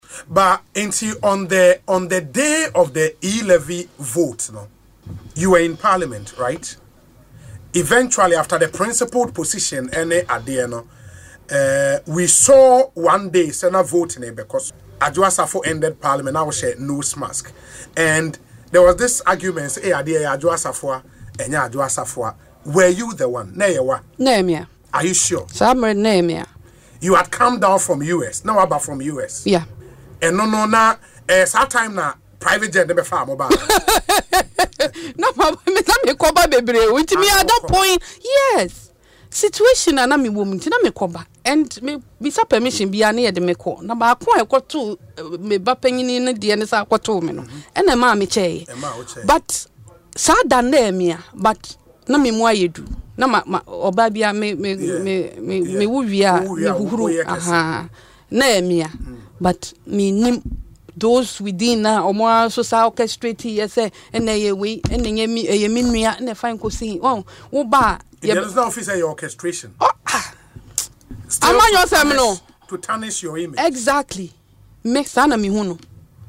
In an interview on Asempa FM’s Ekosii Sen show, Adwoa Safo confirmed that it was indeed her who was present on the day of the vote.